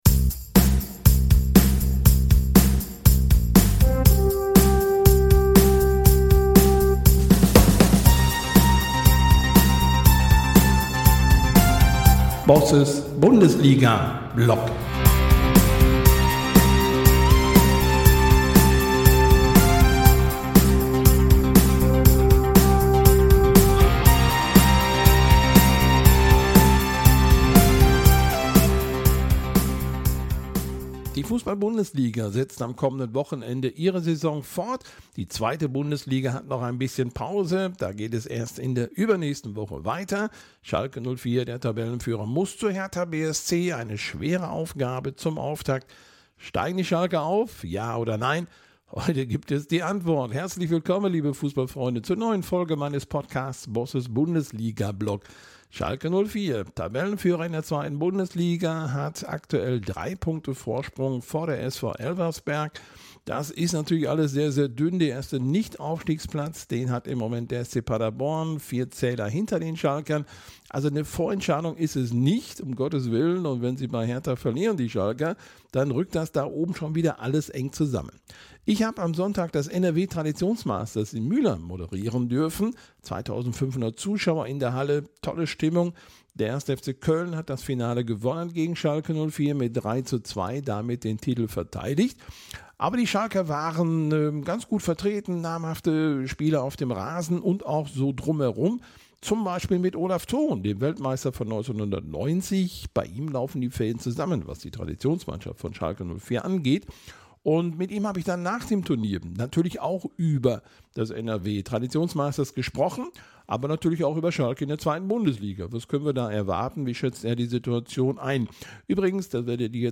Ein Gespräch über Gegenwart, Zukunft und Vergangenheit, denn die Traditionsmannschaft ist ebenfalls ein Thema.